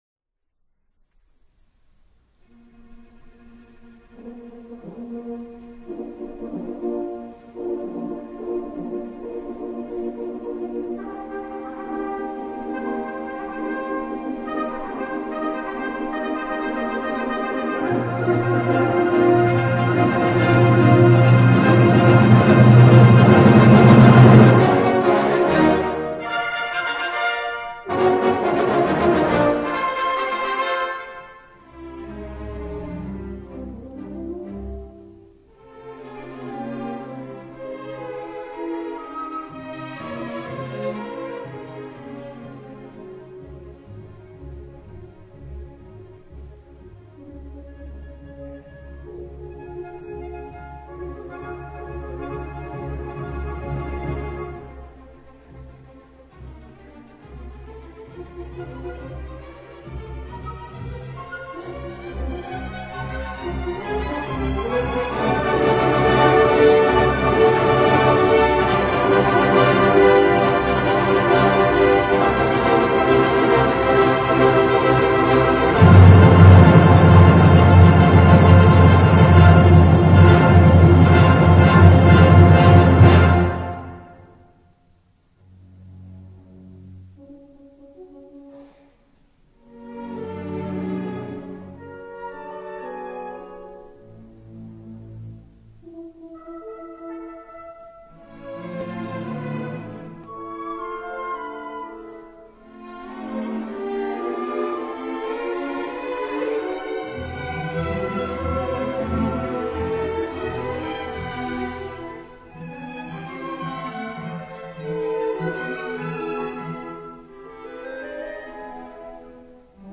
Public live concert
with the Philarmonic Symphony Orchestra
Le maestro est égal à lui-même : la dynamique et le souffle incroyable poussés à l'extrème nous montre un Bruckner beaucoup moins sage que la tradition nous le laisse entendre...